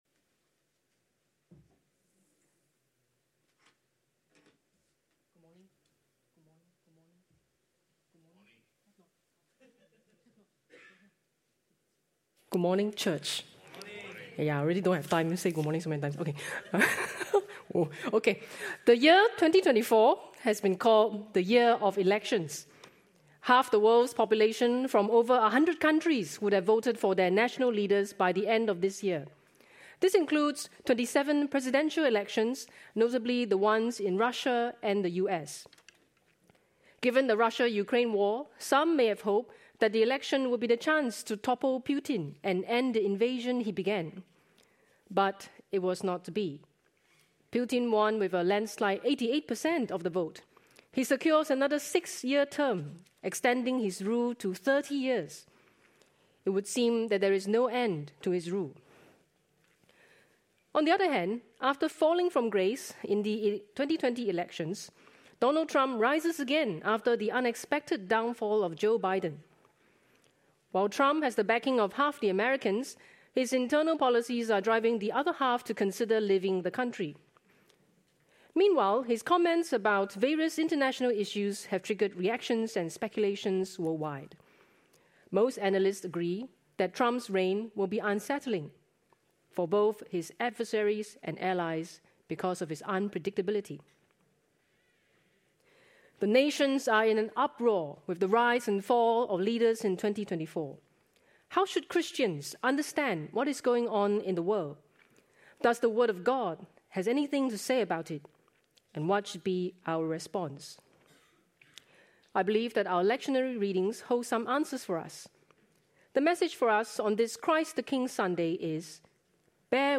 Sermons preached at the English Congregation of Church of the Good Shepherd (Singapore).